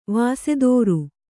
♪ vāsedōru